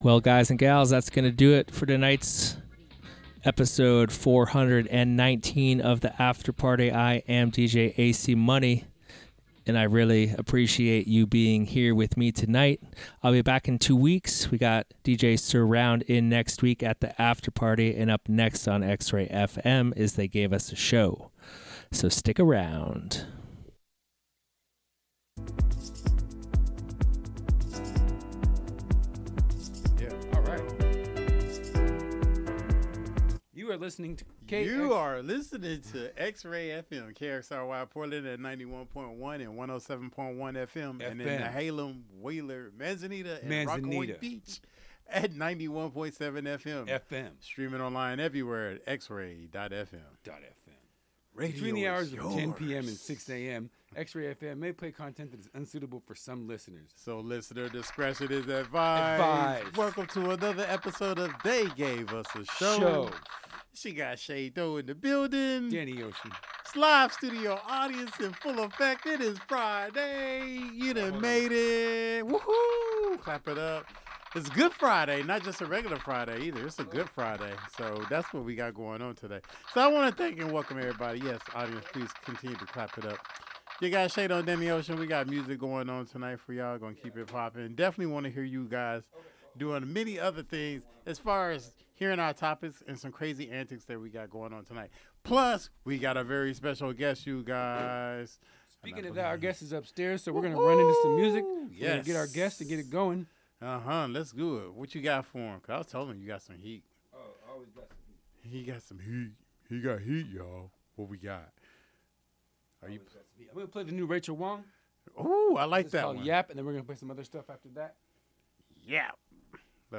Plus, catch interviews from talented artists.